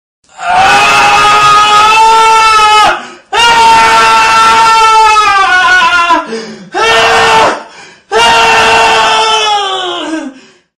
دانلود آهنگ صدای داد و فریاد مرد 3 از افکت صوتی انسان و موجودات زنده
جلوه های صوتی